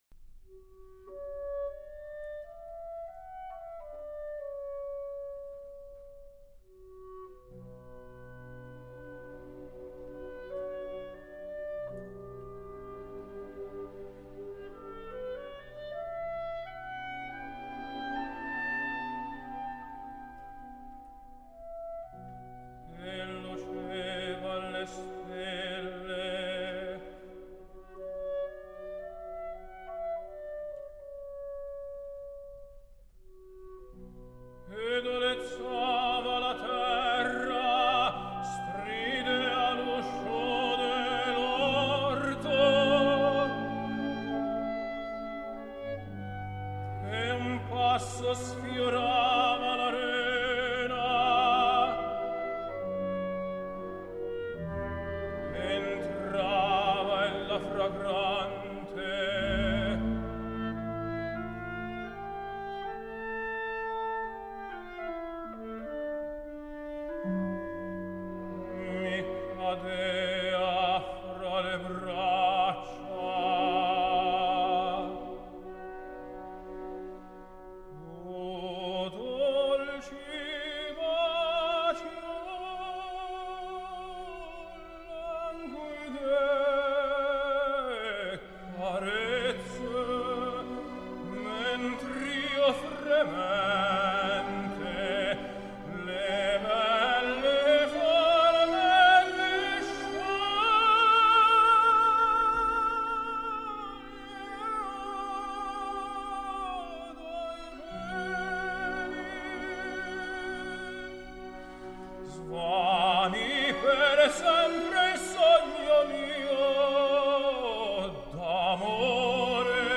类别：古典音乐
他的声音雄浑高亢，在高音处仍能保持相当柔软度却不显阴性特质